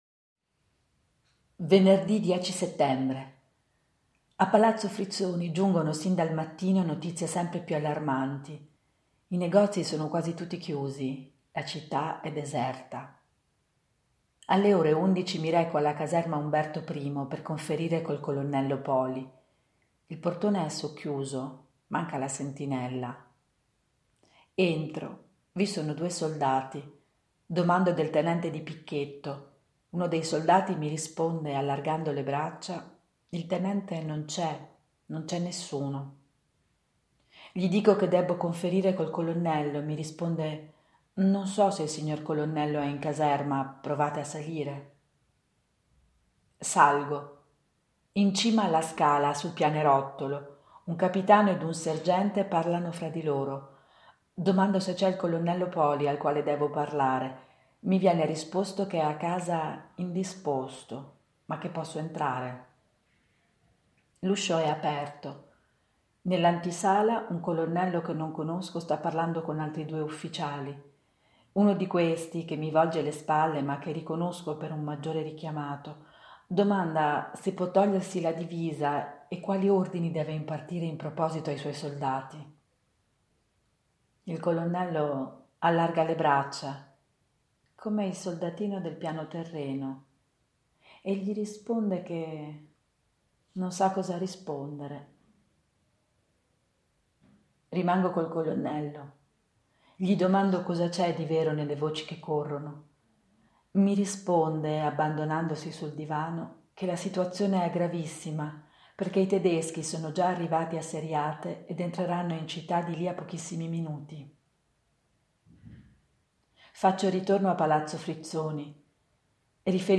Lettura di estratti da Sereno Locatelli Milesi, Nove giorni a Palazzo Frizzoni 8-16 settembre 1943, Edizioni Orobiche Bergamo, Bergamo 1945